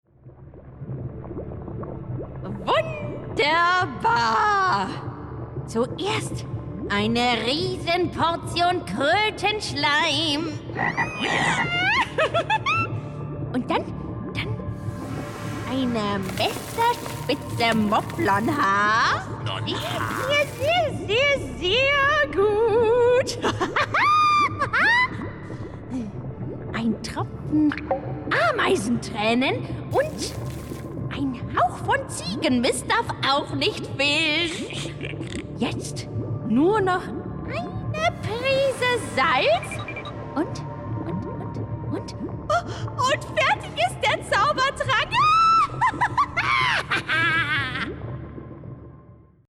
sehr variabel
Jung (18-30)
Audio Drama (Hörspiel)